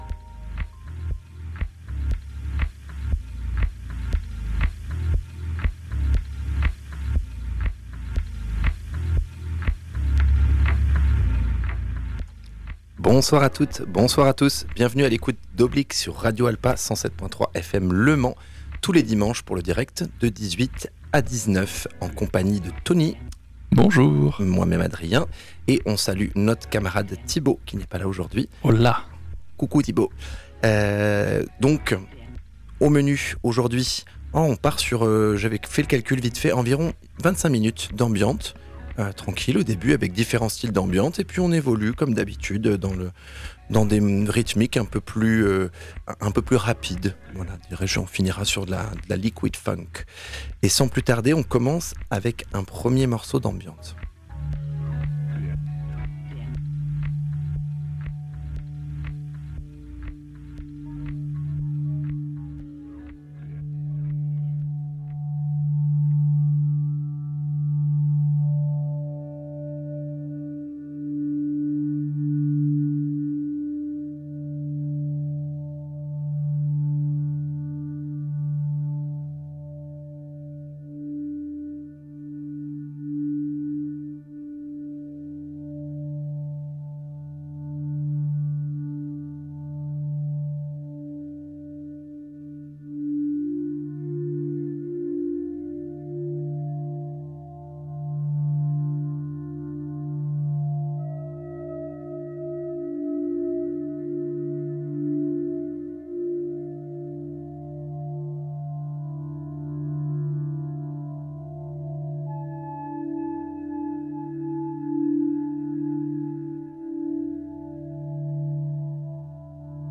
ELECTRONICA